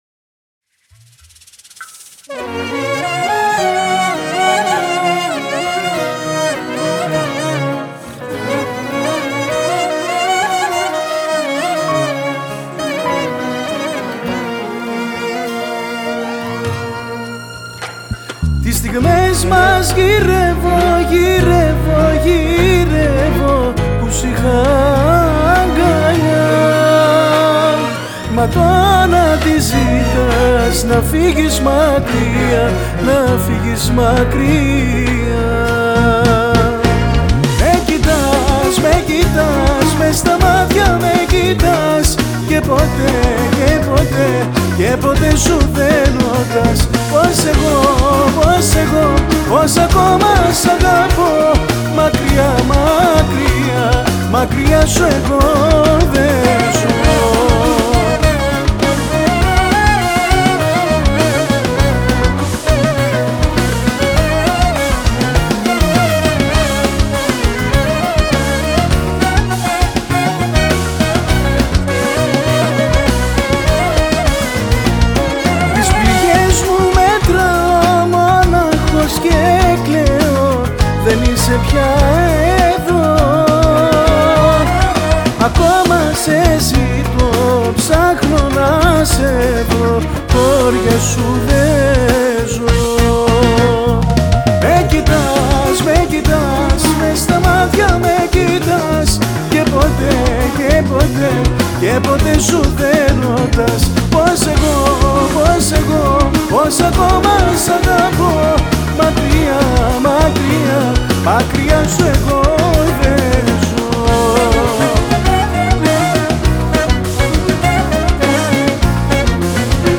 Жанр: ΛΑΪΚΆ
ΚΛΑΡΙΝΟ
ΠΛΗΚΤΡΑ
ΚΡΟΥΣΤΆ
ΚΙΘΆΡΕΣ
ΜΠΑΣΟ